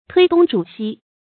推東主西 注音： ㄊㄨㄟ ㄉㄨㄙ ㄓㄨˇ ㄒㄧ 讀音讀法： 意思解釋： 猶言推三阻四。